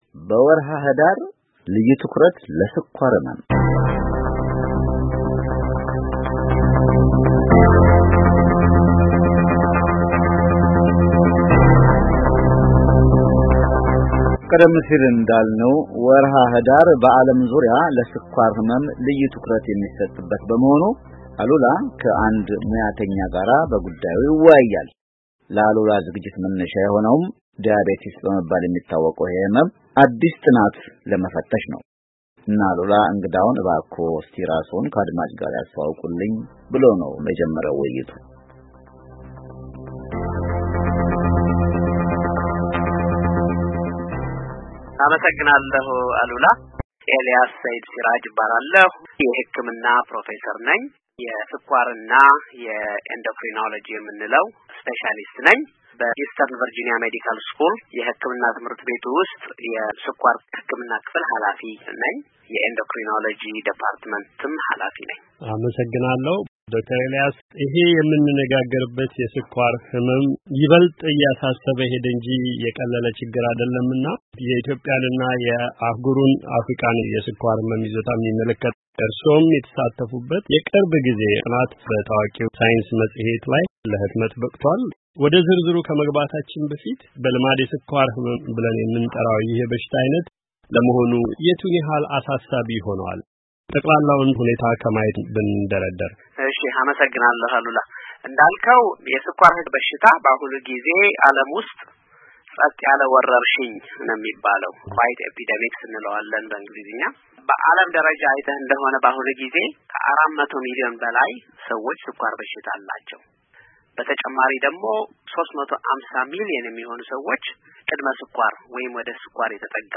ቃለ ምልልስ